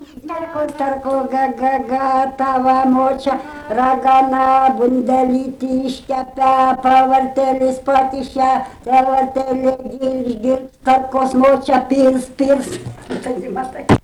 smulkieji žanrai
Obeliai
vokalinis